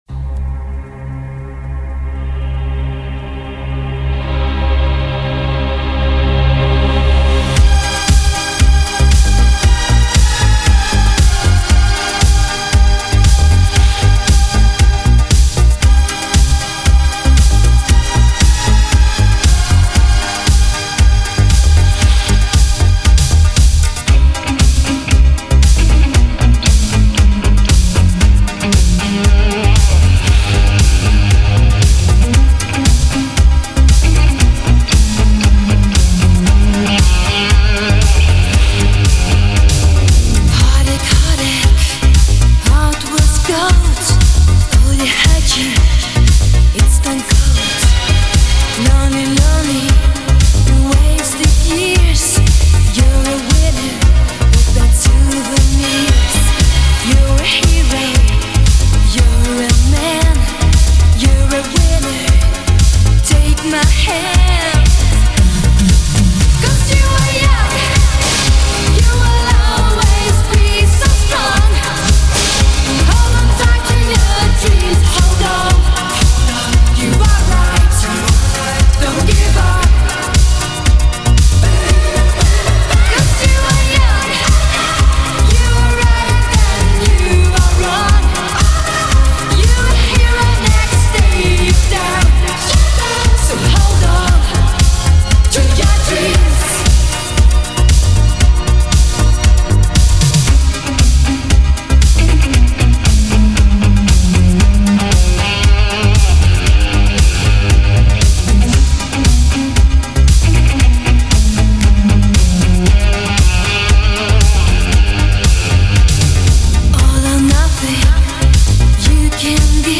Ретро музыка
Зарубежные хиты 80-х , музыка 80-х